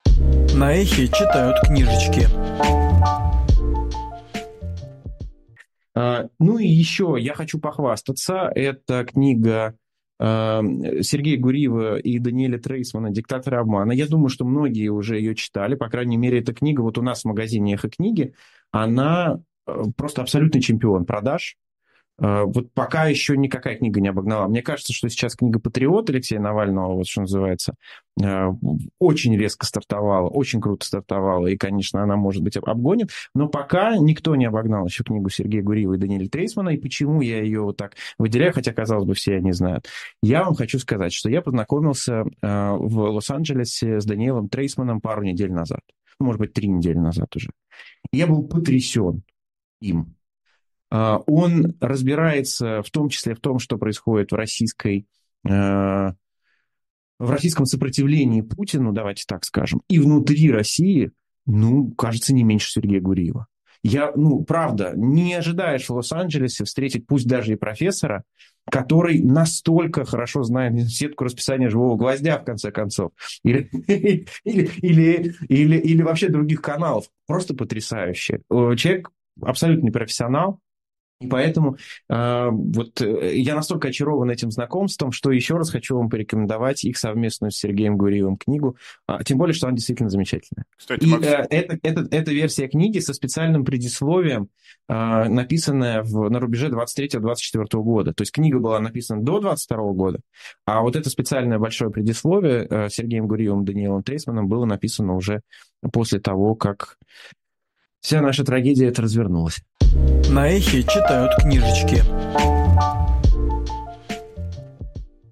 Фрагмент эфира «Книжное казино» от 4 ноября